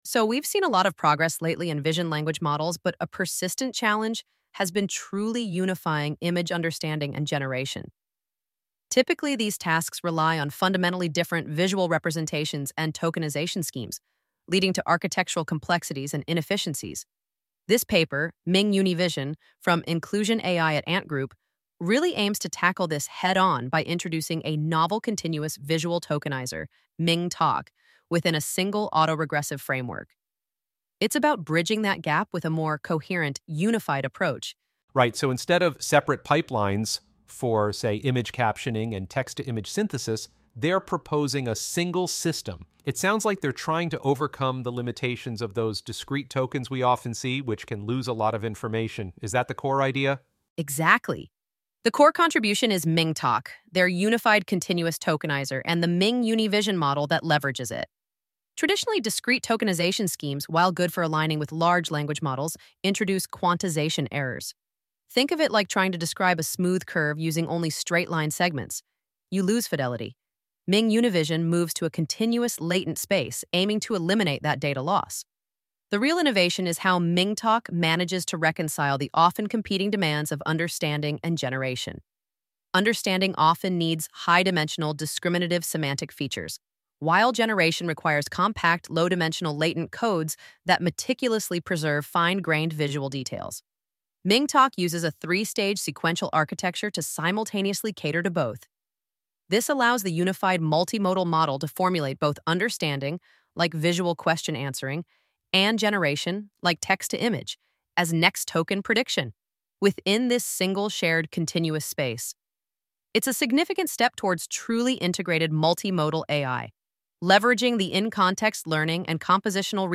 AI Audio Lecture + Q&A